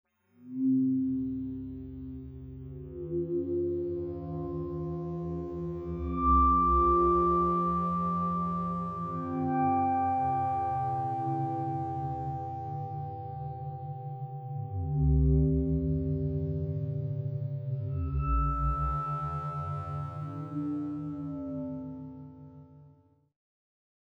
Amplitudes help the sonic character a lot.
Figure 7. Analysis with smoothed frequencies over 110 frames, and smoothed amplitudes over 10+100 frames.